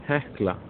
Hekla (Icelandic pronunciation: [ˈhɛhkla]
Is-Hekla_pronunciation.ogg.mp3